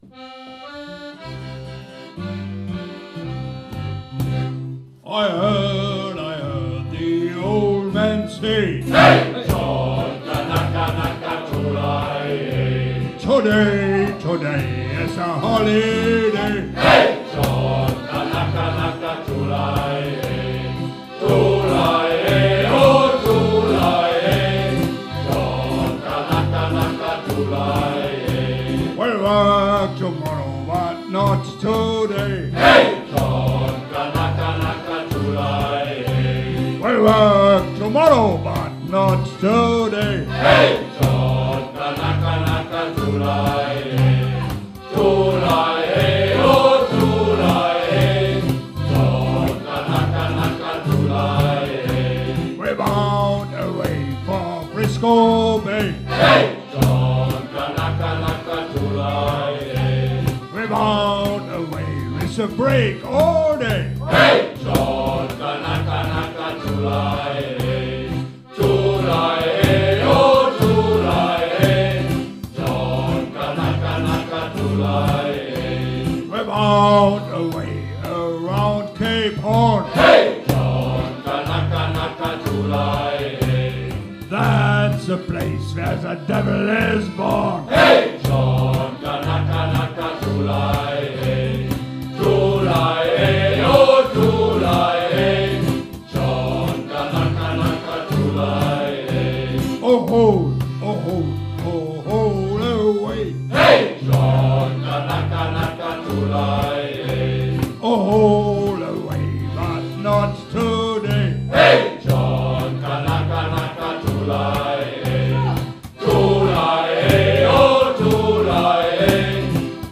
Shanty-Chor der Marinekameradschaft